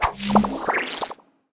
Wep Forceball Throw Sound Effect
wep-forceball-throw.mp3